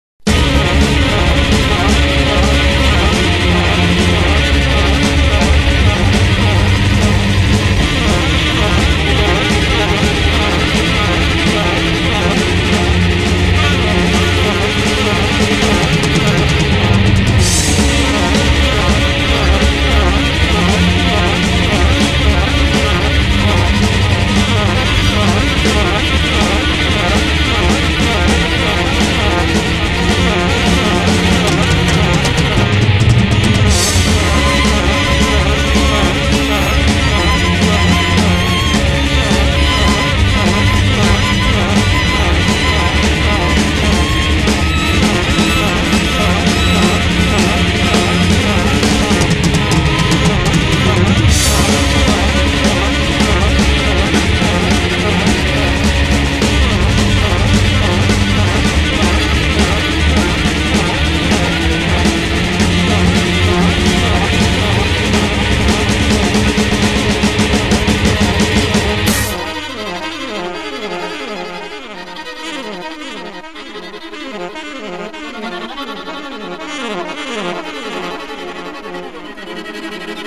guitar and drums
avantjazz saxophonist